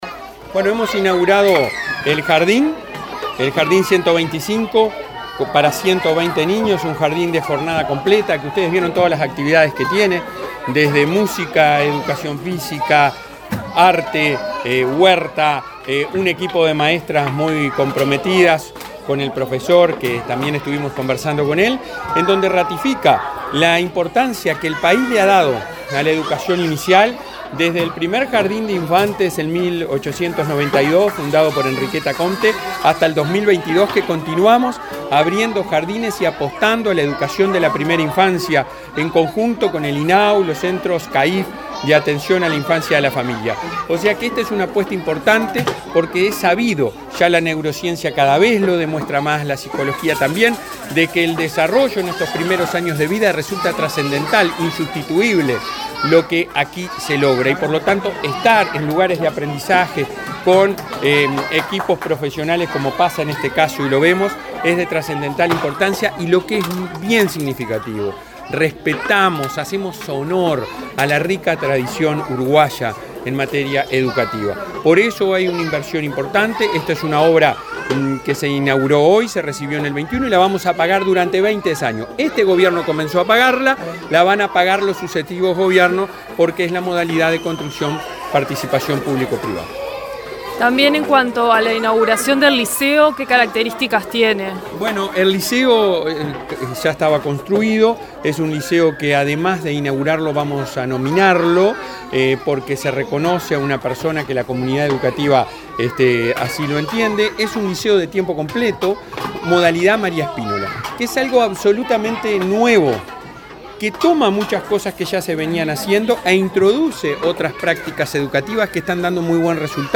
Entrevista al presidente de la ANEP, Robert Silva
El presidente de la Administración Nacional de Educación Pública (ANEP), Robert Silva, dialogó con Comunicación Presidencial en Mercedes, Soriano,